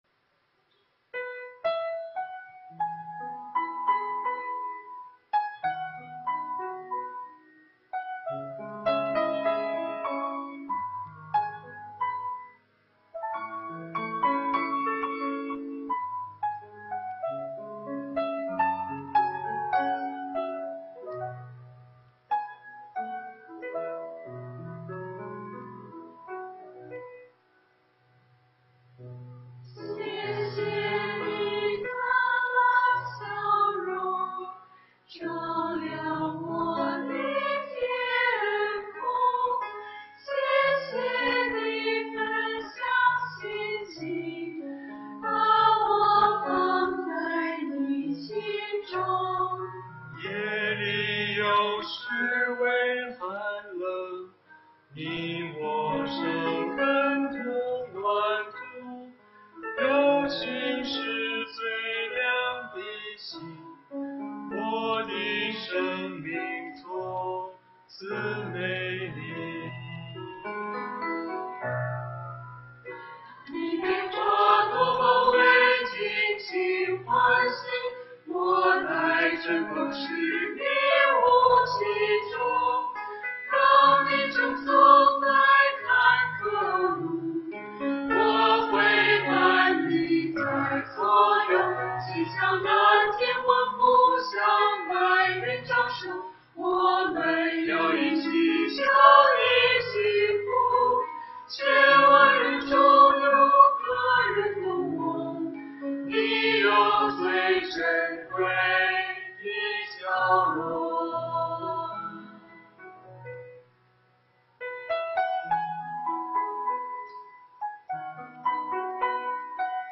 中秋赞美会
团契名称: 联合诗班
诗班献诗